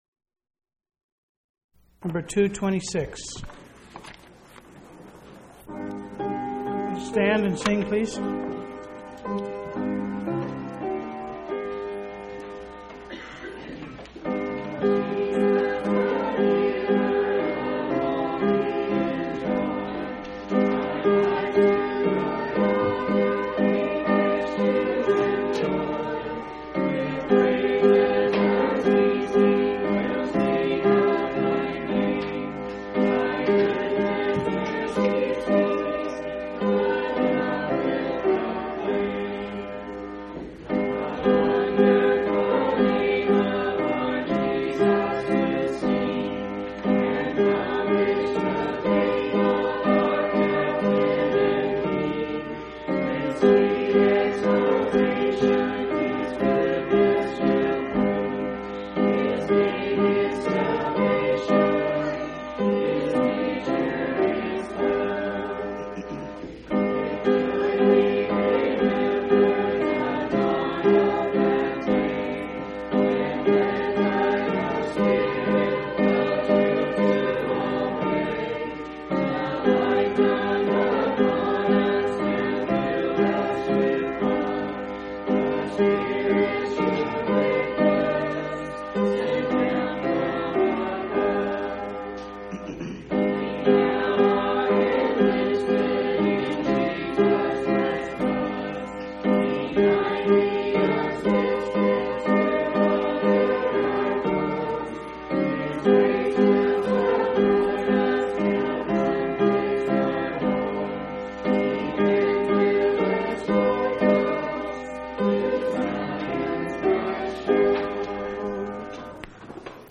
1/28/1996 Location: Phoenix Local Event